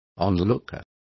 Complete with pronunciation of the translation of onlooker.